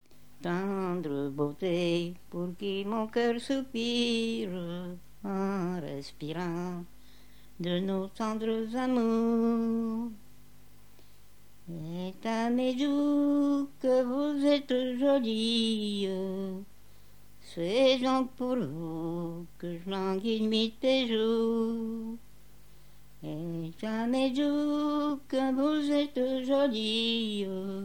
Chansons traditionnelles et populaires
Pièce musicale inédite